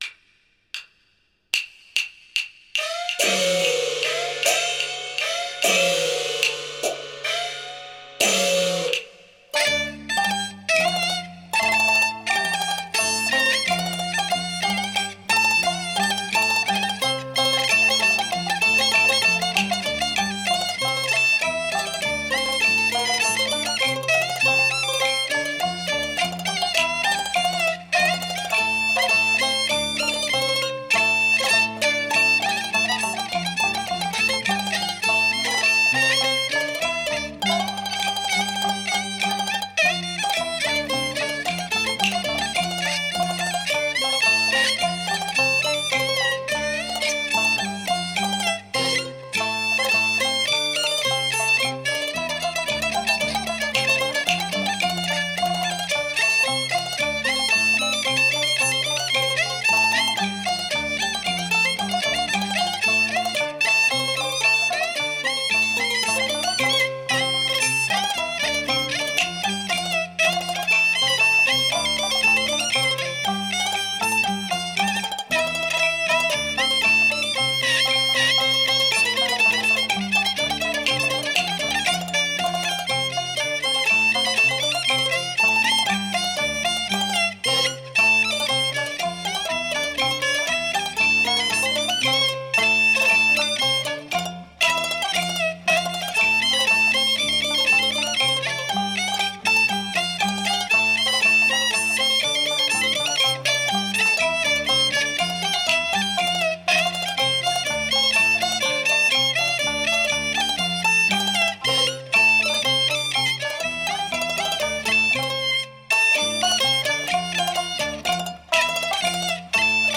〖京剧〗
京    胡